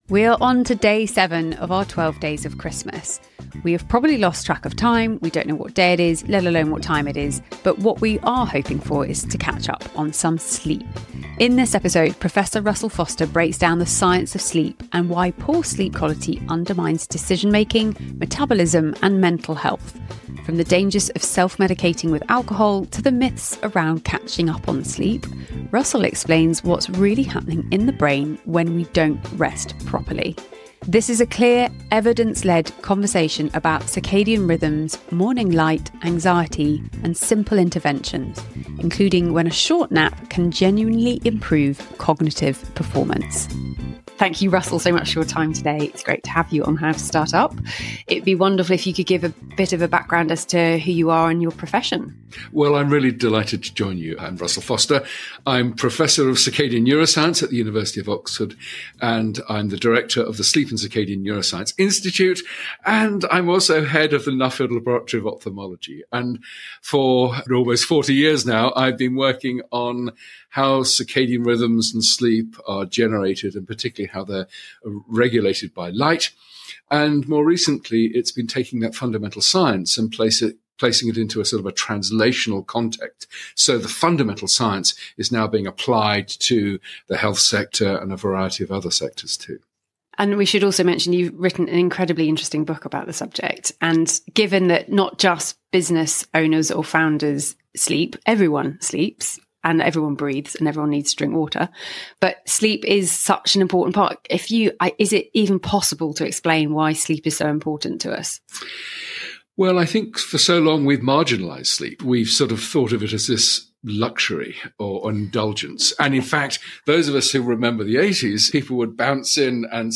Russell Foster, Professor of Circadian Neuroscience & the Head of the Sleep and Circadian Neuroscience Institute at the University of Oxford as well as the author of Life Time explains why a good night’s sleep is so important, as well as what ‘good’ means to an individual, plus how to balance your sleep schedule with building your business.